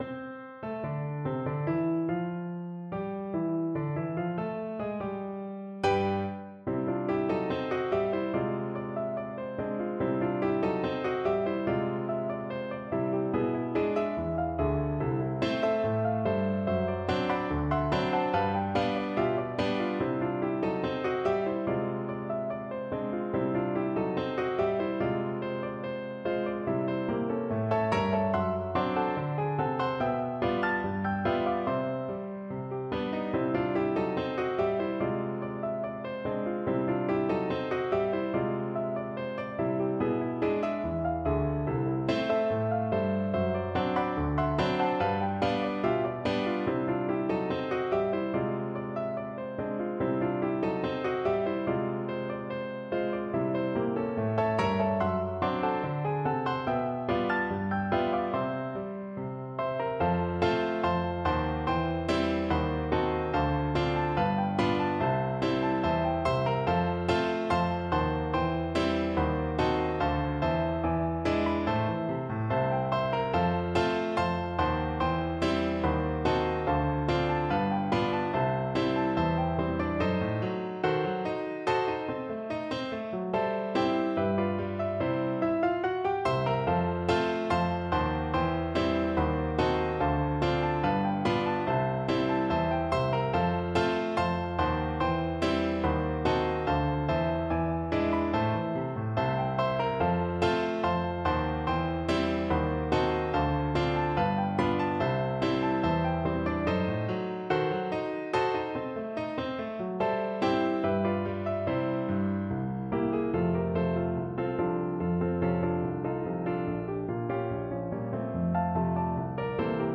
No parts available for this pieces as it is for solo piano.
= 72 Very Slow March Time
2/4 (View more 2/4 Music)
C major (Sounding Pitch) (View more C major Music for Piano )
Piano  (View more Advanced Piano Music)
Jazz (View more Jazz Piano Music)